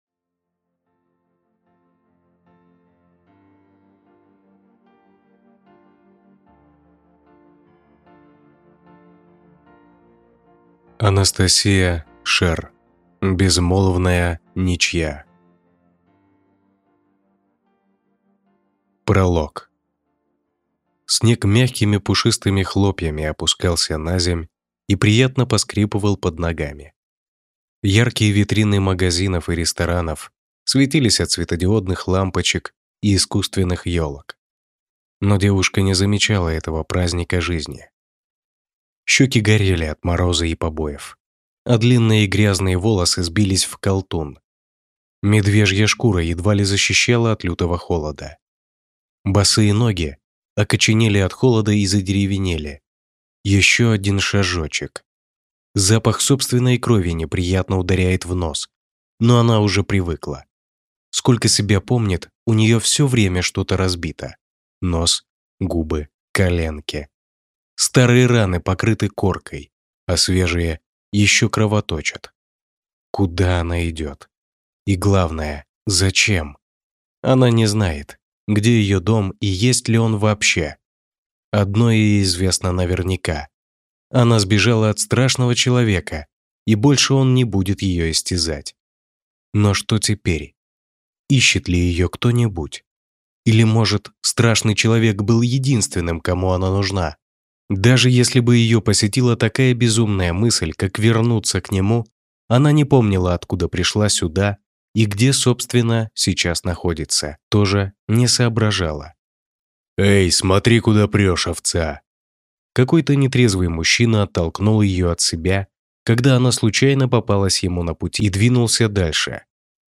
Аудиокнига Безмолвная ничья | Библиотека аудиокниг